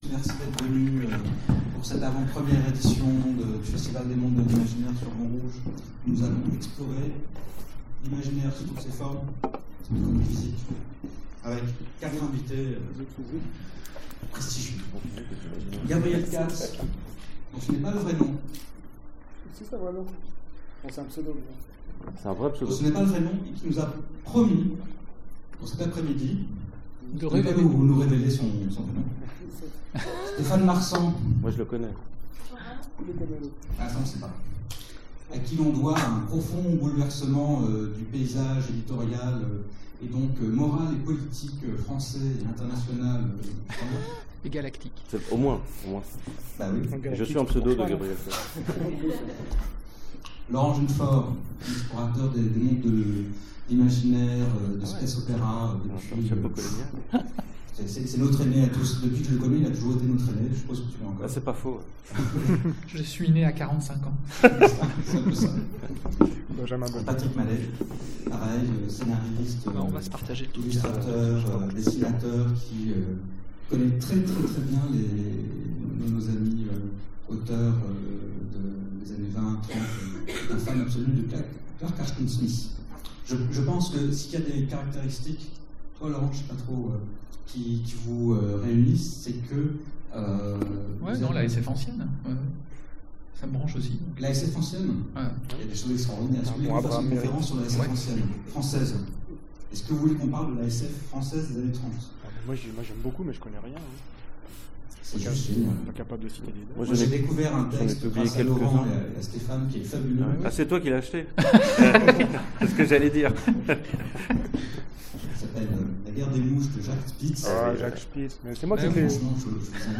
Conférence FMI 2015 Livres et jeux